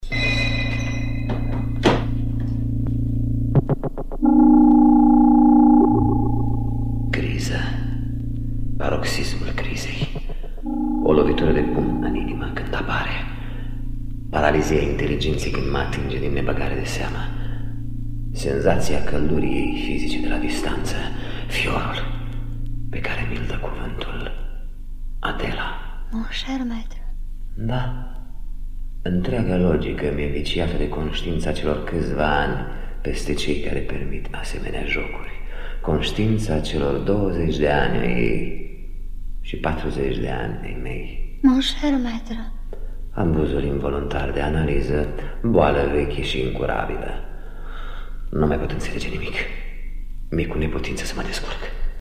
Secvență din Adela… (Ștefan Iordache – în rolul introvertitului Emil Codrescu; Violeta Andrei – în rolul misterioasei Adela)